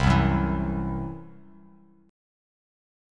CMb5 chord